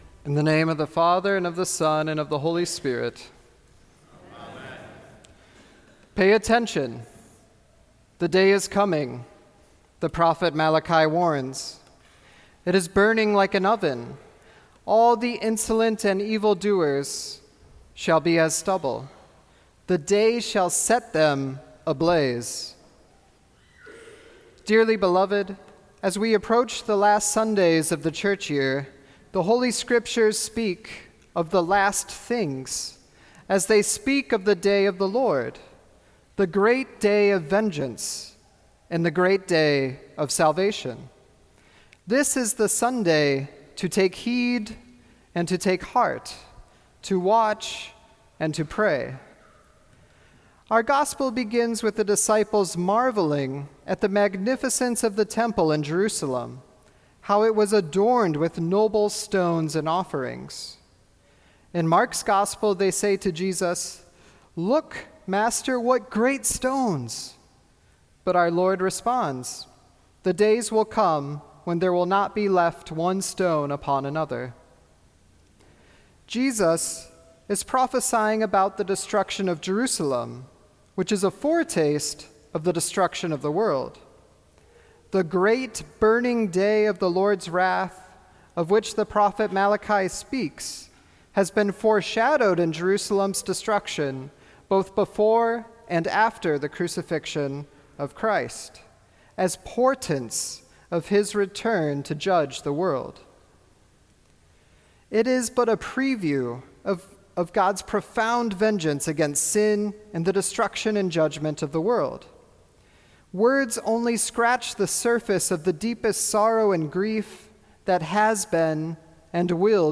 Sermon for Twenty-third Sunday after Pentecost